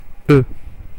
Ääntäminen
Ääntäminen France (Paris): IPA: [ø] Tuntematon aksentti: IPA: /ə/ Haettu sana löytyi näillä lähdekielillä: ranska Käännöksiä ei löytynyt valitulle kohdekielelle.